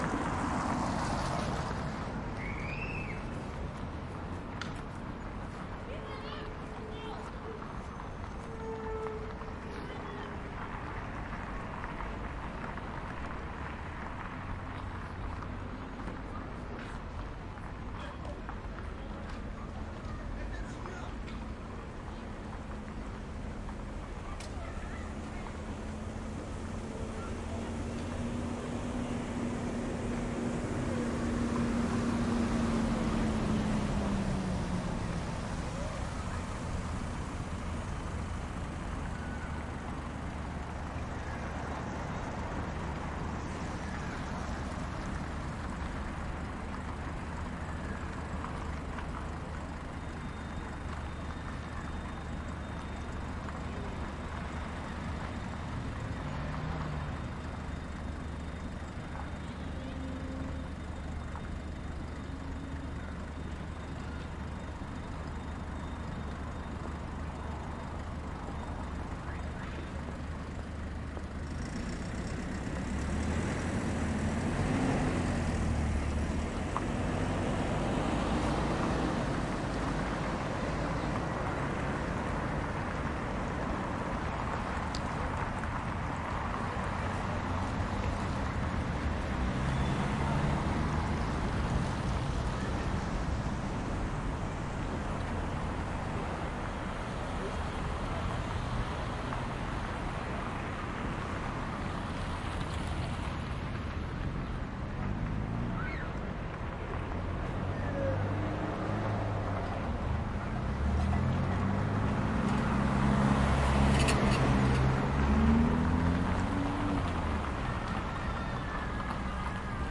吉他armonics与淡入效果。整个音轨已经放慢了速度。混音是使用免费软件“Audacity”创建的，并用简单的麦克风录制。